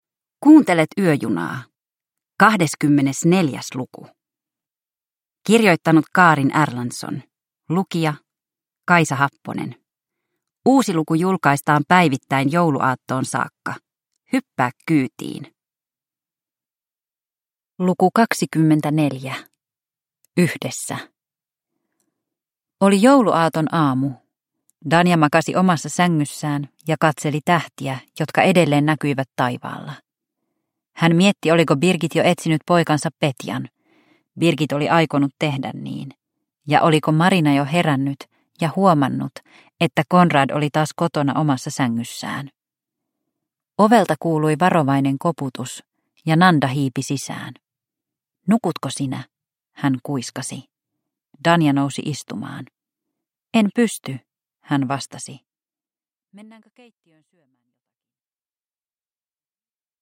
Yöjuna luku 24 – Ljudbok